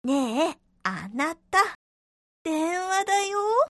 携帯電話用音声集
携帯電話の着信音声として使える音声25個セットです。